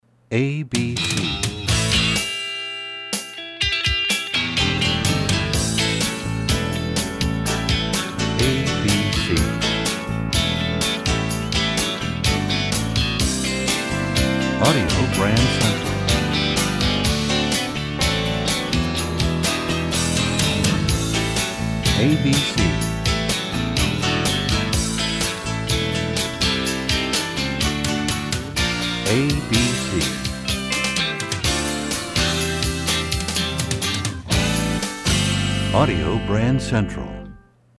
Genre: Jingles.